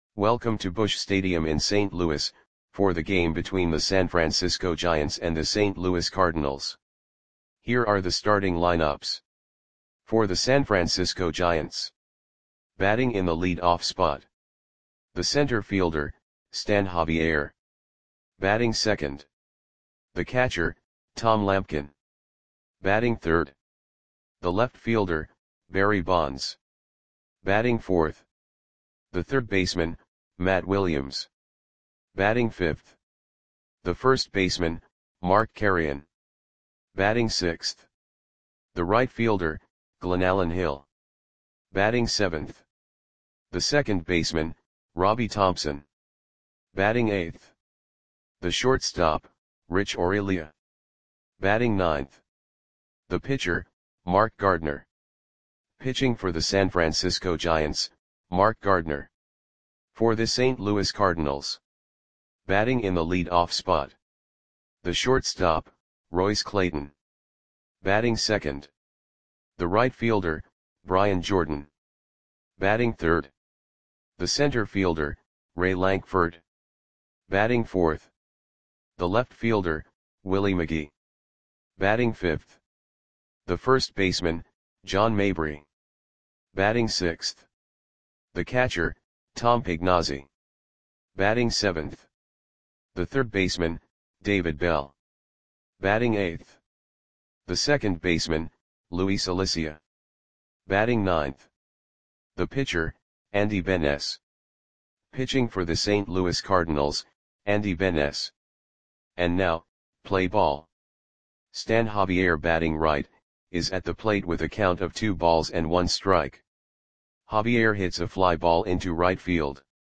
Audio Play-by-Play for St. Louis Cardinals on May 8, 1996
Click the button below to listen to the audio play-by-play.